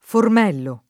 Formello [ form $ llo ]